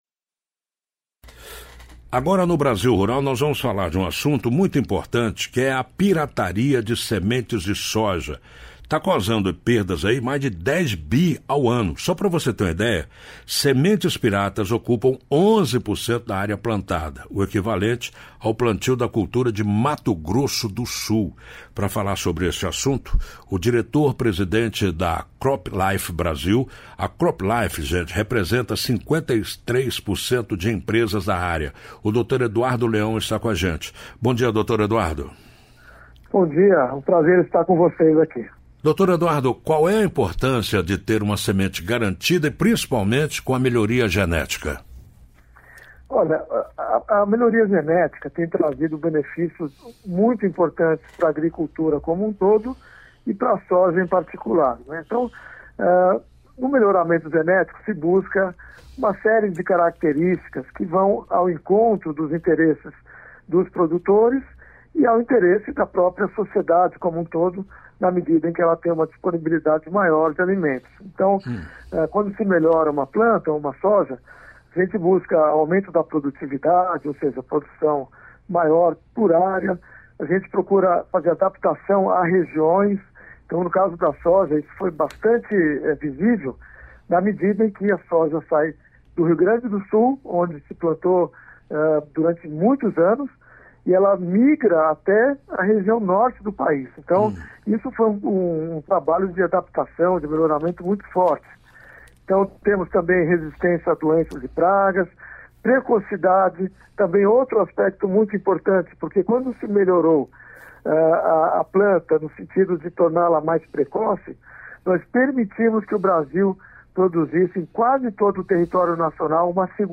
[Rádio] Pirataria de sementes gera prejuízos bilionários - CropLife